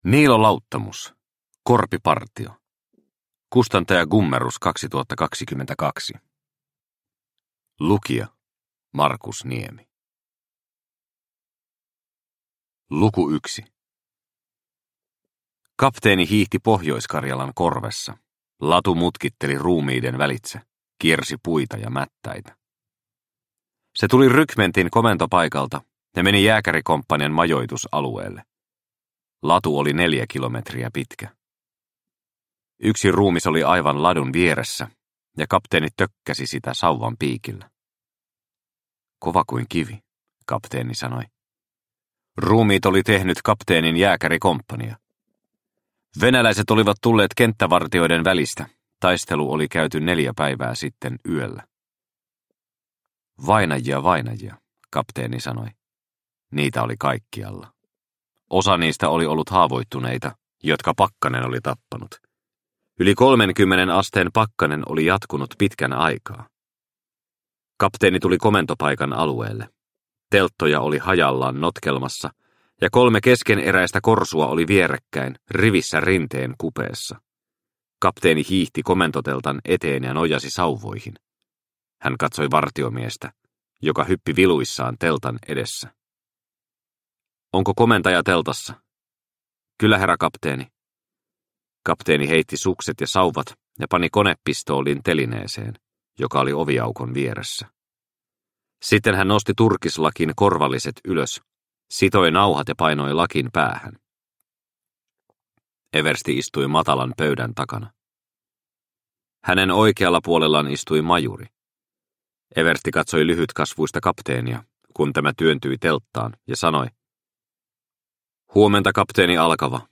Korpipartio – Ljudbok – Laddas ner